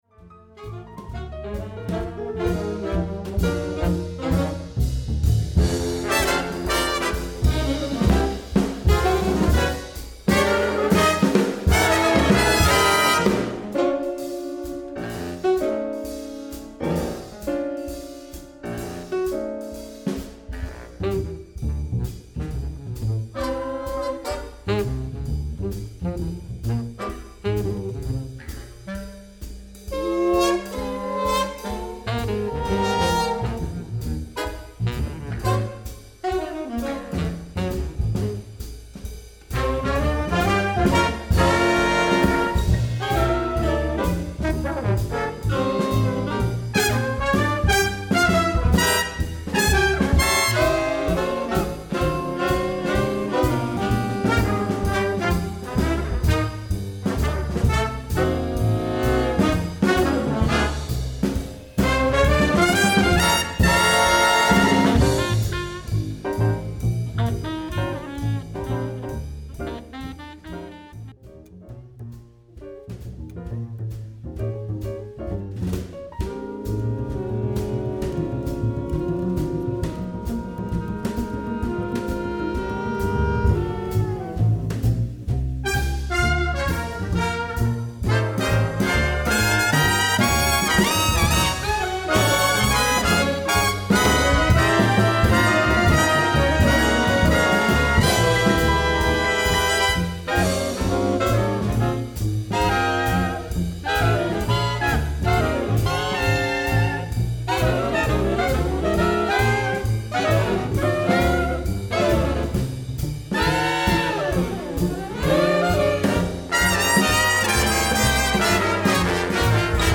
Store/Music/Big Band Charts/ORIGINALS/PORTRAIT
Doubles: soprano sax, clarinet, bass clarinet
Solos: baritone, bass